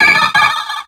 Cri de Coquiperl dans Pokémon X et Y.